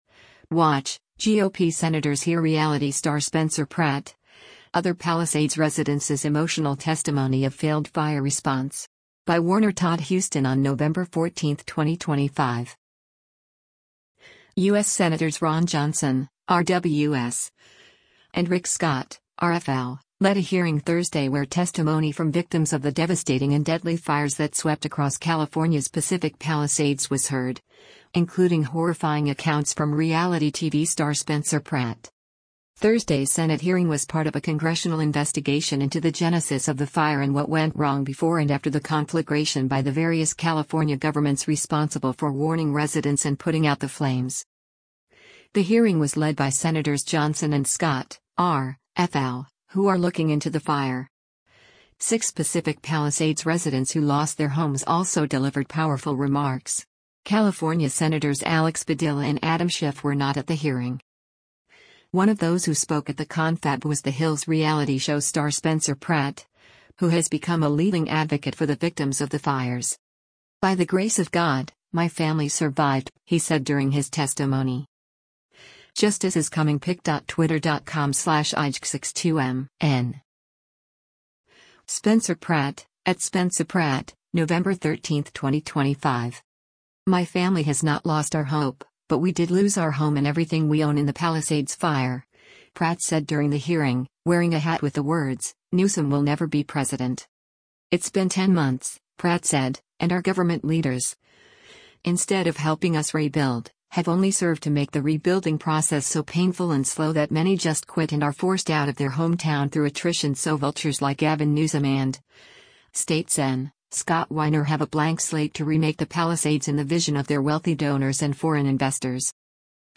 Watch: GOP Senators Hear Reality Star Spencer Pratt, Other Palisades Residences' Emotional Testimony of Failed Fire Response
Six Pacific Palisades residents who lost their homes also delivered powerful remarks.